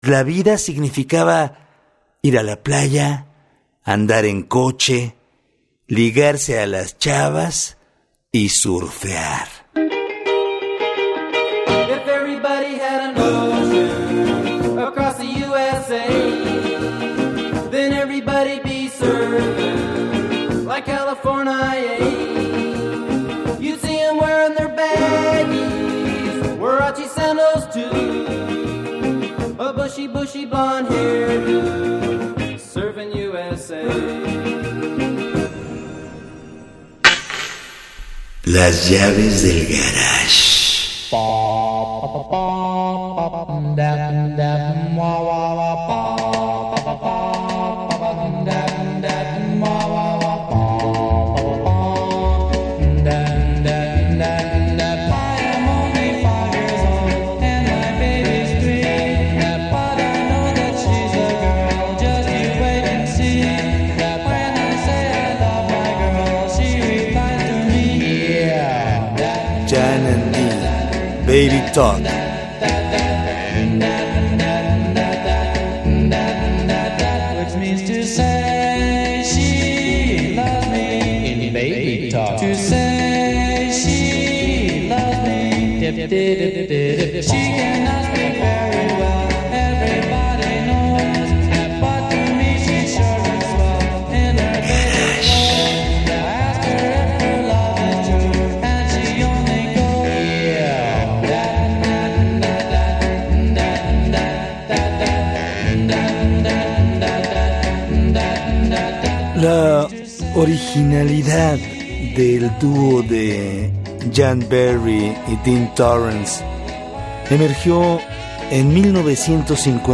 surf